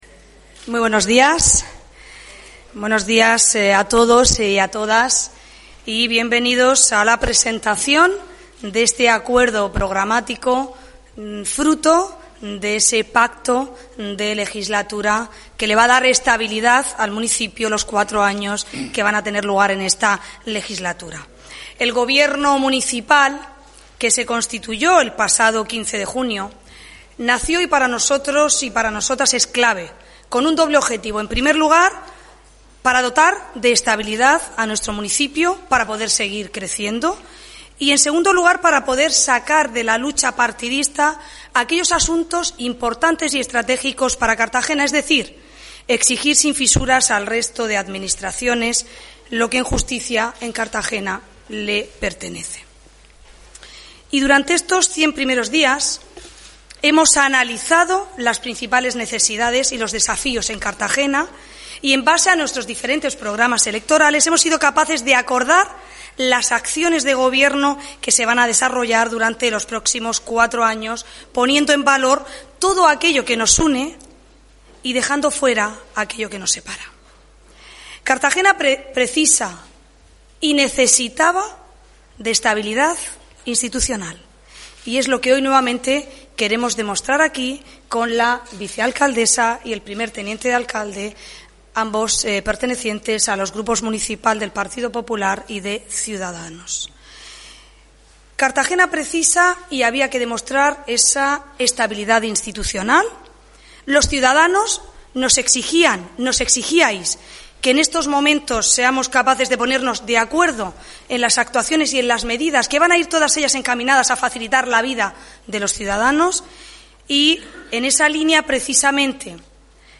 Audio: Presentaci�n del programa de gobierno municipal 2019/2023 (MP3 - 13,51 MB)